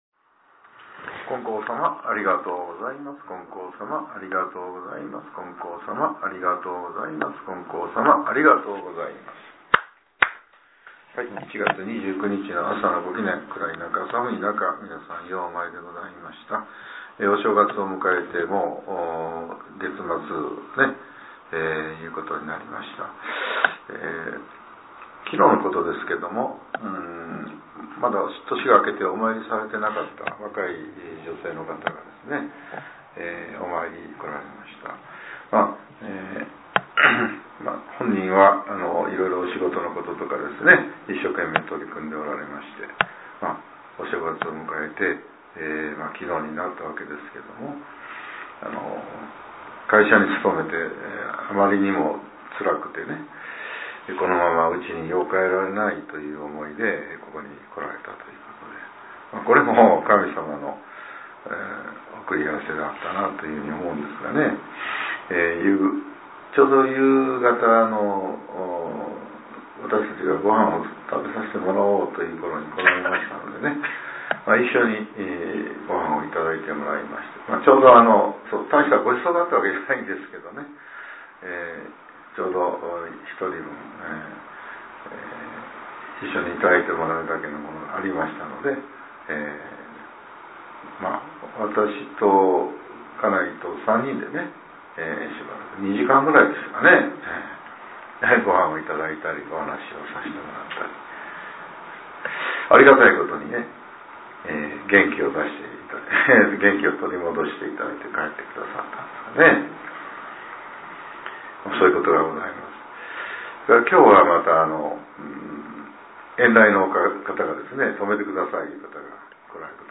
令和８年１月２９日（朝）のお話が、音声ブログとして更新させれています。 きょうは、前教会長による「見え方が変わると」です。